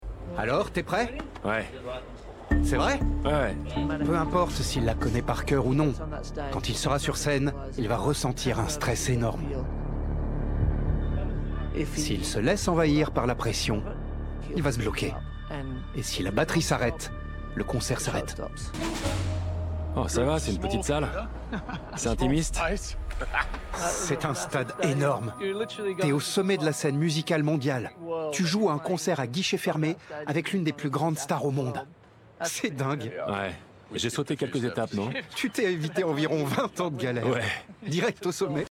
Extrait série documentaire Voice Over - Voix 1